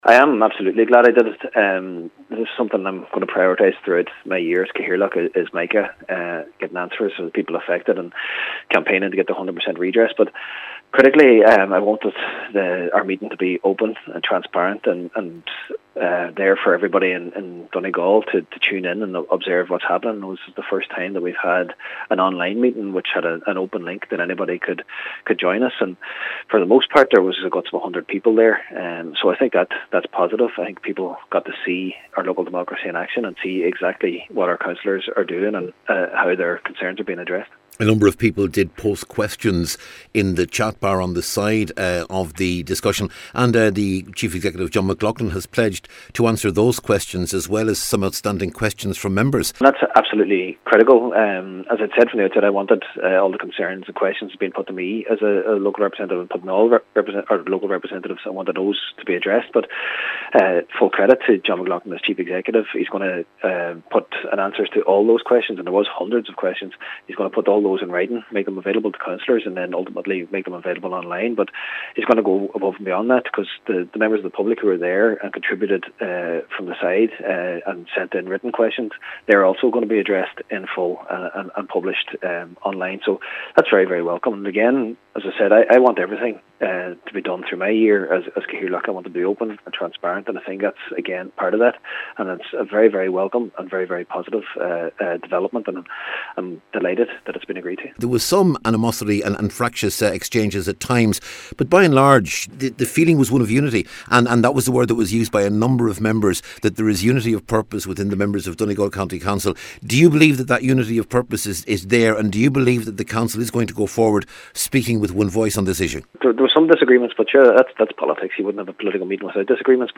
The meeting was called by Cathaoirleach Cllr Jack Murray, who says it was important that the meeting took place in public.